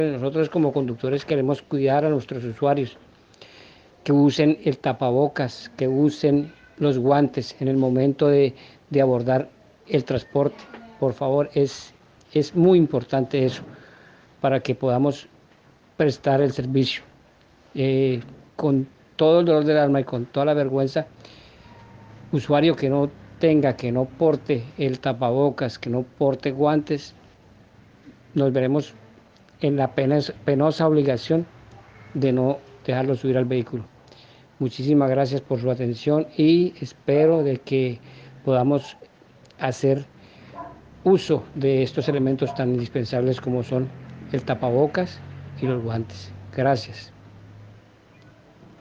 «Hay mucha gente que se sube como si nada y nos preocupa esta situación. La empresa nos protege a cada unos de nosotros día y noche y contamos con el programa de lavar las manos cada momento al llegar de viaje, pero necesitamos del apoyo de todos», dijo preocupado uno de los conductores.